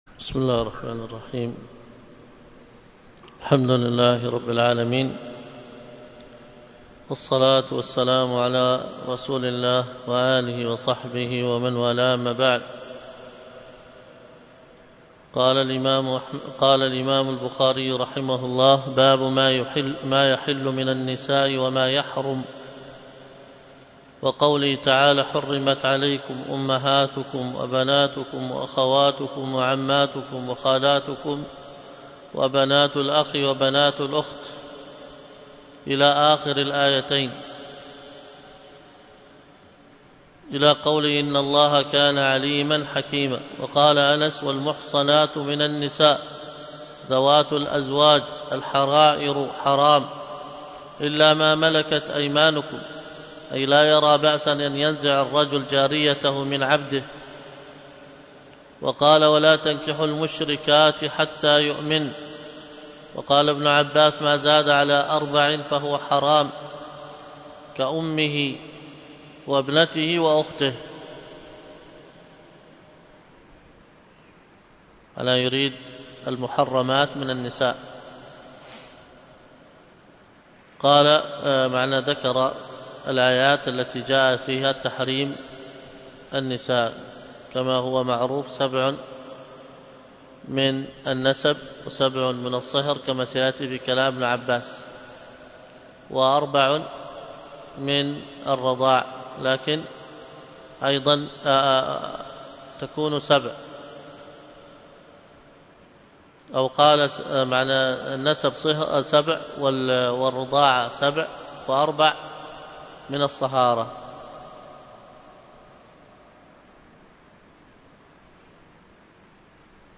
الدرس في كتاب النكاح من صحيح البخاري 31